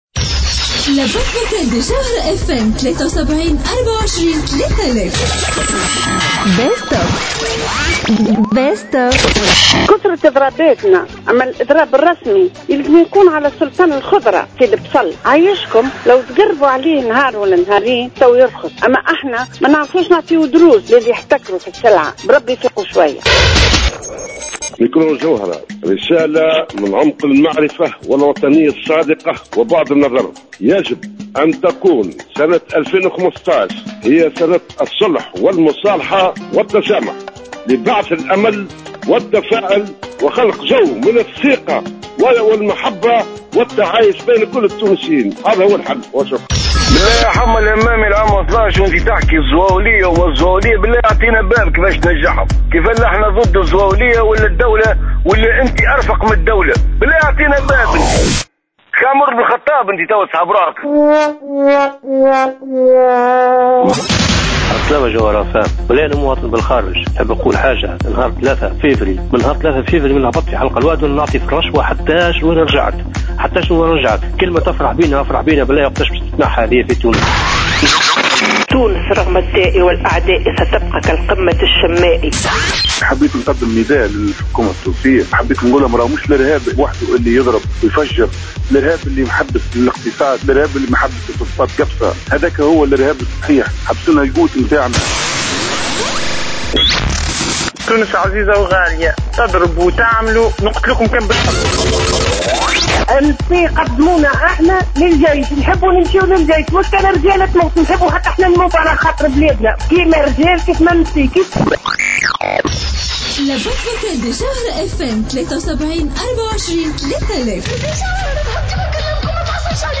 Un Tunisien résident à l'étranger : il faut payer des pots-de-vin à chaque passage